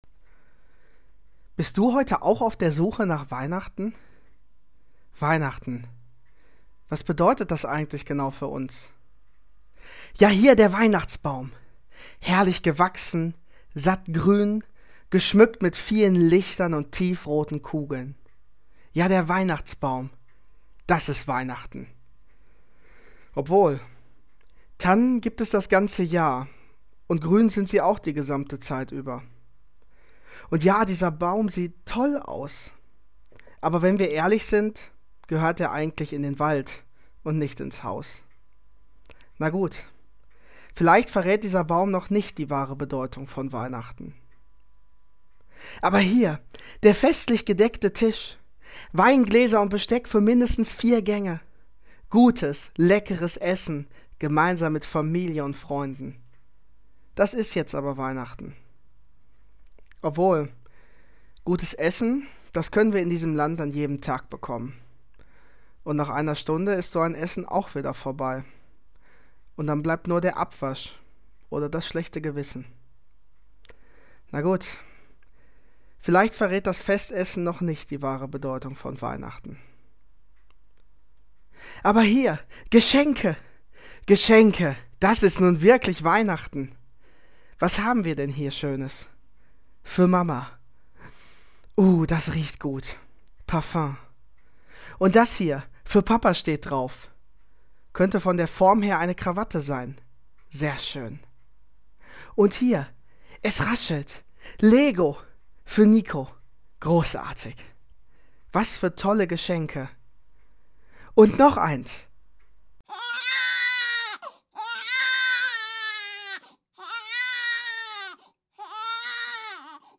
Die Weihnachtsandacht nochmal anhören - Evangelische Freikirche Hüls